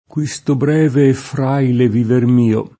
fragile [fr#Jile] agg. — poet. frale [fr#le], poet. ant. fraile [fr#ile], spec. in senso fig.: questo breve et fraile viver mio [